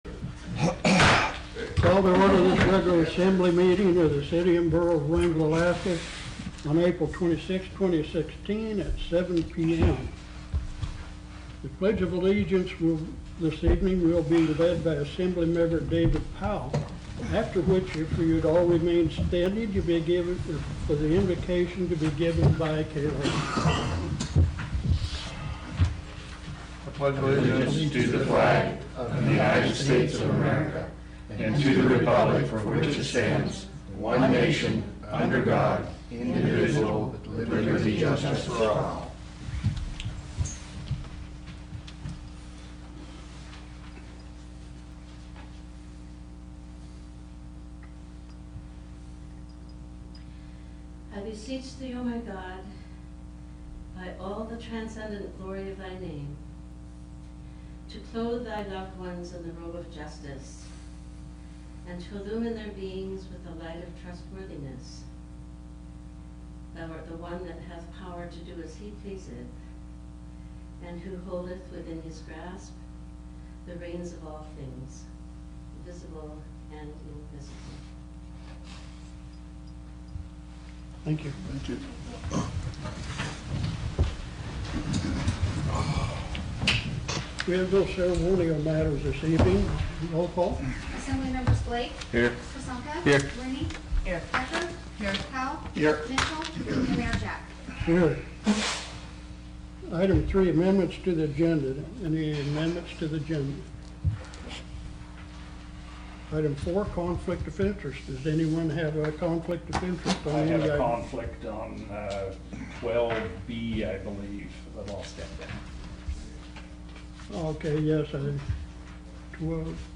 Wrangell's Borough Assembly held its regular meeting Tuesday, April 26th, 2016 in the Assembly Chambers.
City and Borough of Wrangell Borough Assembly Meeting AGENDA April 26, 2016 – 7:00 p.m. Location: Assembly Chambers, City Hall